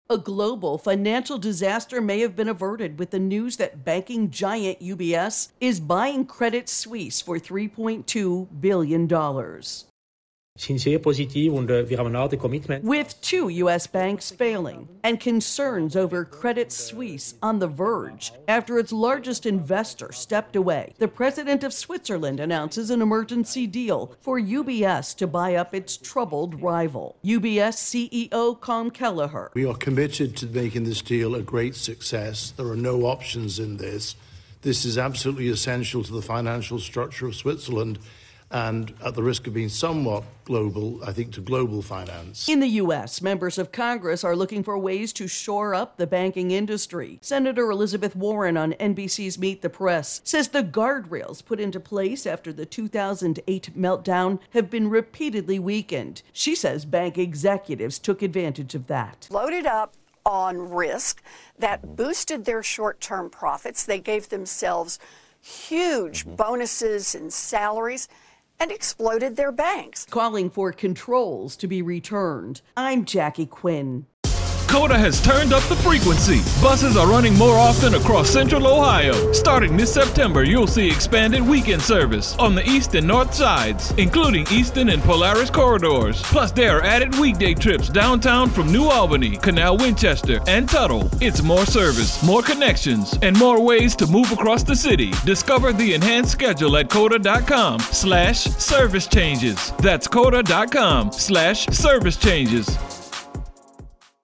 AP correspondent on Switzerland Credit Suisse UBS Bank